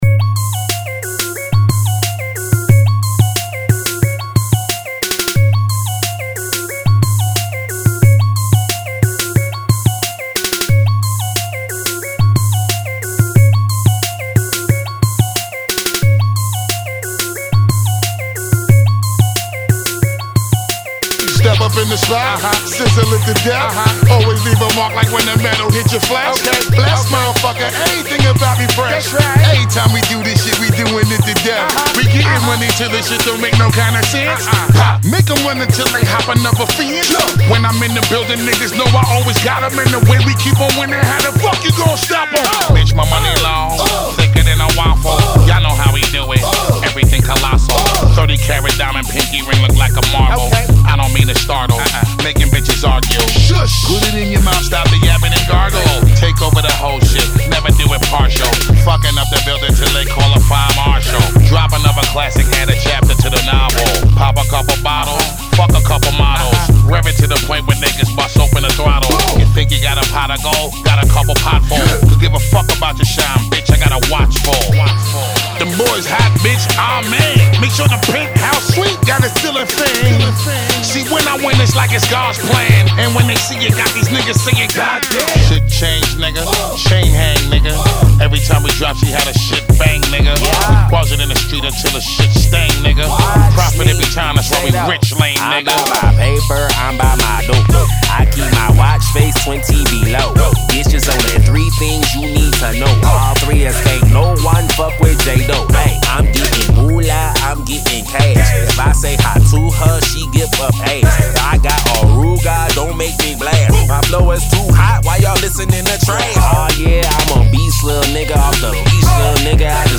Funky [ 90 Bpm ]